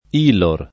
vai all'elenco alfabetico delle voci ingrandisci il carattere 100% rimpicciolisci il carattere stampa invia tramite posta elettronica codividi su Facebook ILOR [ & lor ] s. f. — sigla di Imposta Locale sui Redditi